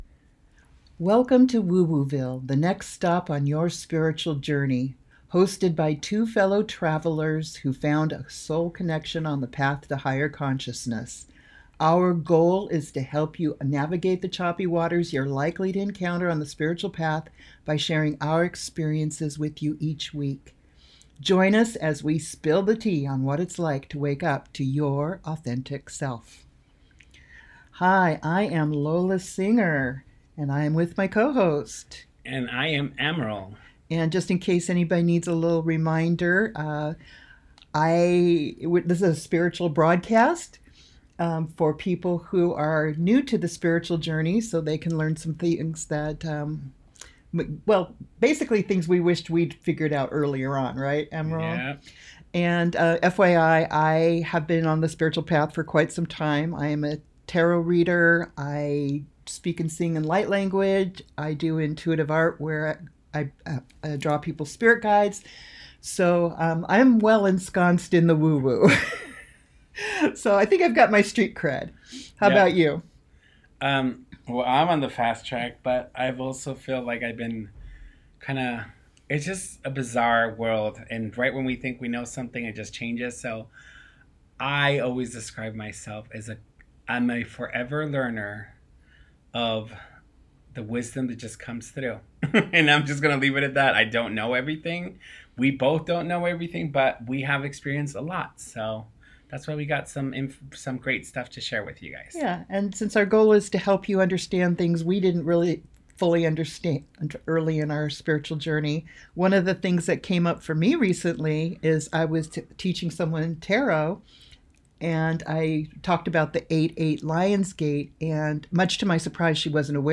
Their grounded, candid conversation opens space for reflection on timelines, intention-setting, and the role of manifestation in daily life.